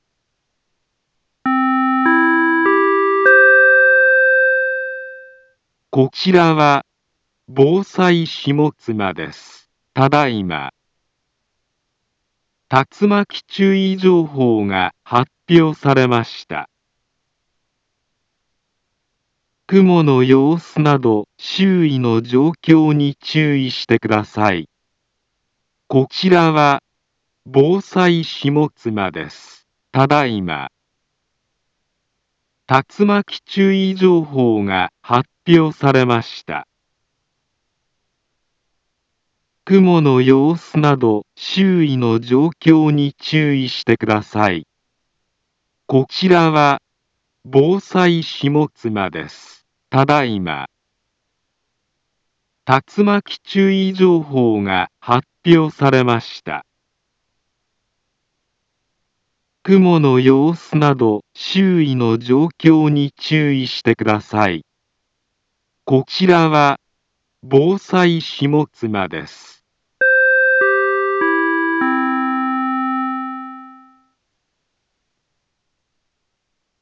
Back Home Ｊアラート情報 音声放送 再生 災害情報 カテゴリ：J-ALERT 登録日時：2021-07-12 18:29:32 インフォメーション：茨城県南部は、竜巻などの激しい突風が発生しやすい気象状況になっています。